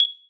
pause-continue-click.wav